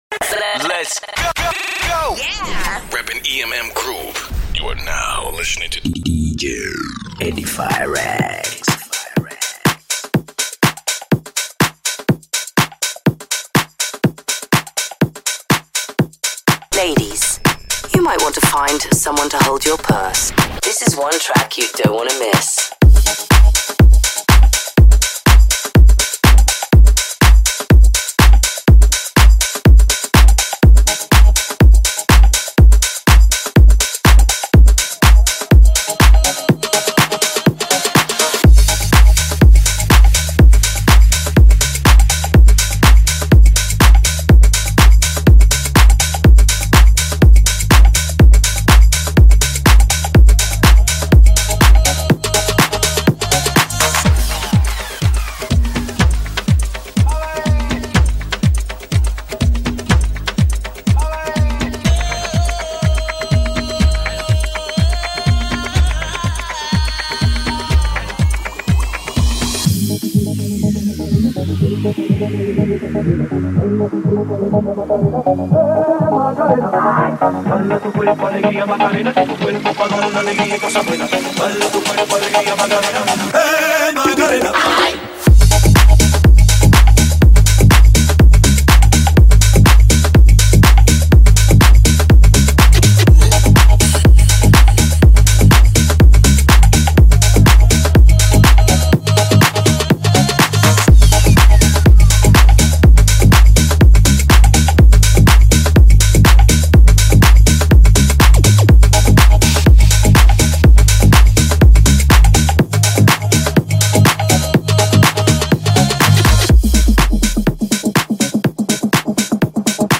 Sri Lankan remix